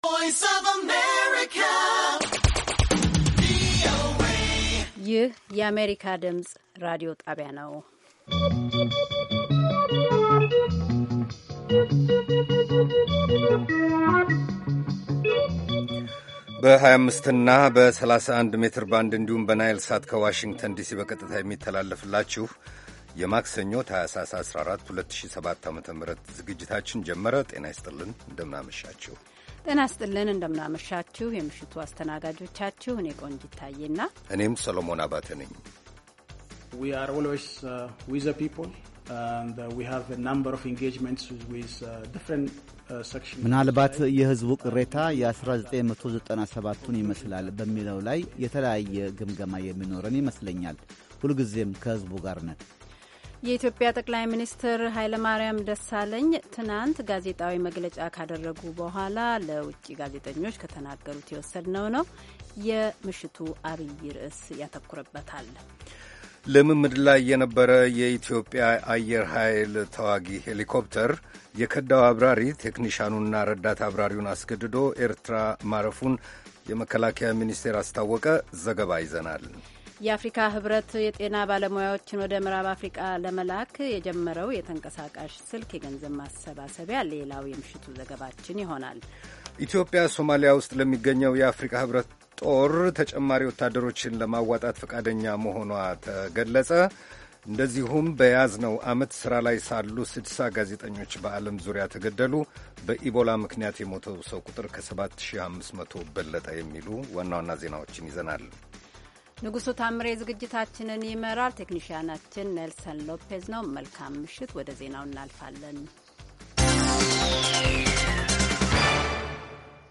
ከምሽቱ ሦስት ሰዓት የአማርኛ ዜና
ቪኦኤ በየዕለቱ ከምሽቱ 3 ሰዓት ጀምሮ በአማርኛ፣ በአጭር ሞገድ 22፣ 25 እና 31 ሜትር ባንድ ከሚያሠራጨው የ60 ደቂቃ ዜና፣ አበይት ዜናዎች ትንታኔና ሌሎችም ወቅታዊ መረጃዎችን የያዙ ፕሮግራሞች በተጨማሪ ከሰኞ እስከ ዐርብ ከምሽቱ 1 ሰዓት እስከ 1 ሰዓት ተኩል በአማርኛ የሚተላለፍ የግማሽ ሰዓት ሥርጭት በ1431 መካከለኛ ሞገድ ላይ አለው፡፡